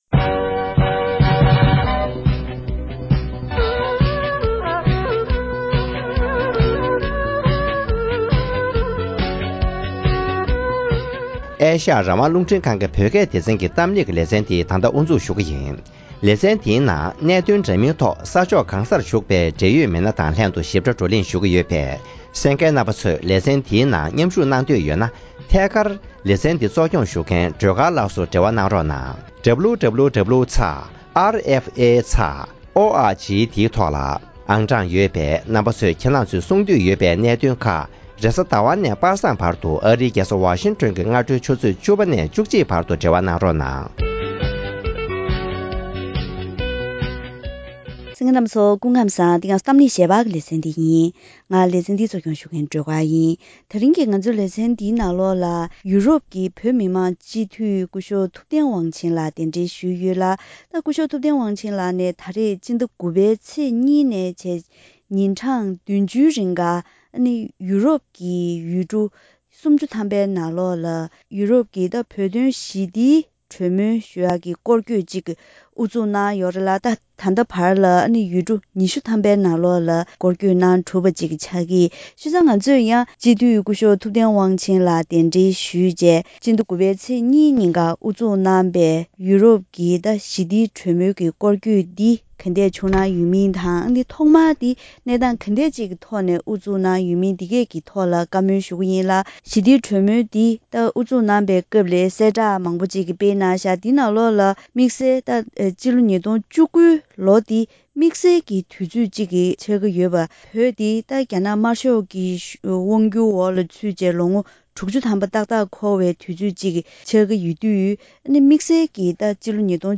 ༄༅།།ཐེངས་འདིའི་གཏམ་གླེང་ཞལ་པར་ལེ་ཚན་ནང་།